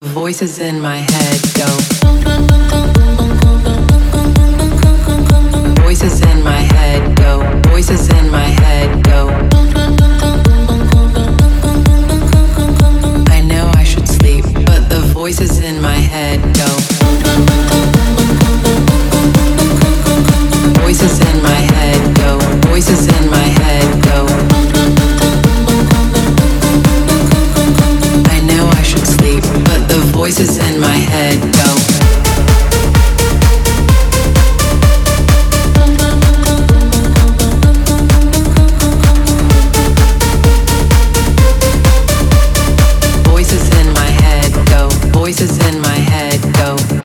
транс , melodic house